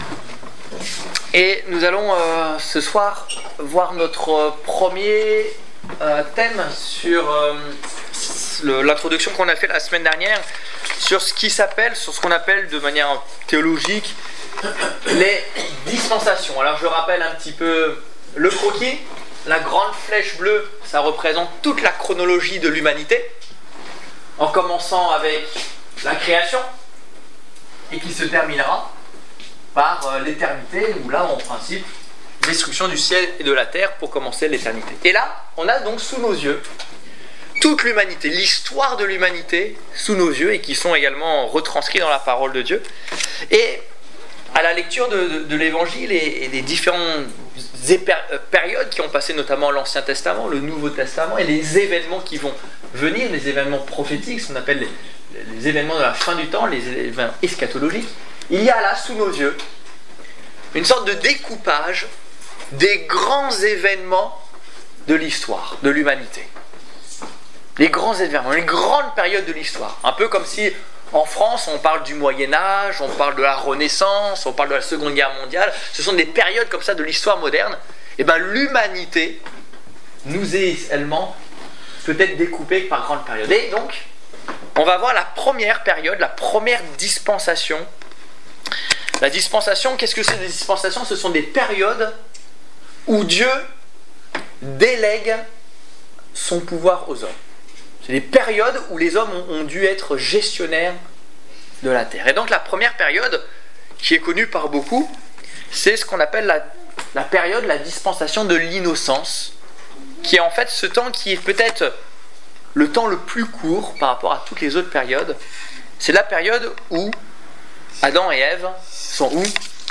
Étude biblique du 18 février 2015